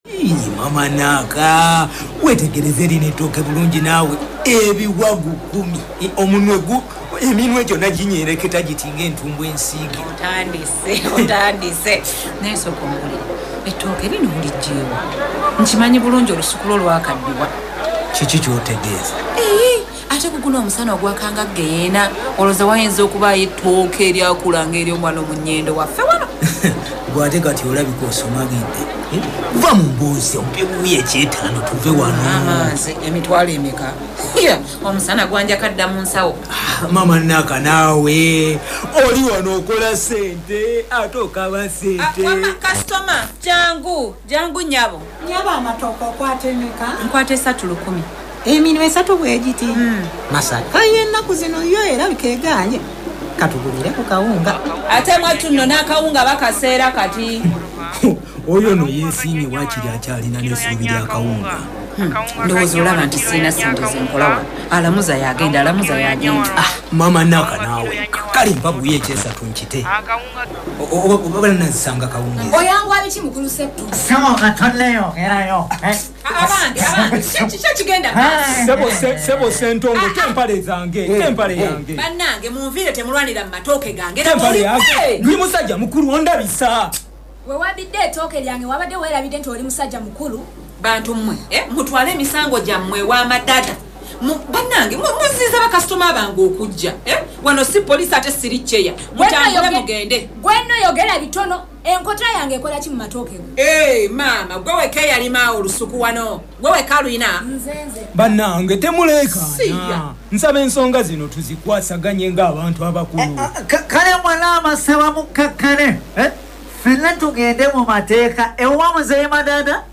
‘A Changing Masaka City’ is a four-part radio drama series written, performed and produced by Arts Applied Uganda. The four episodes tackle environmental justice, climate change, resilience, and community visions for an ‘ideal city’.